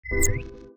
UIClick_Soft Dreamy Whistle Wobble 02.wav